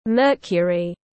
Mercury /ˈmɜːrkjəri/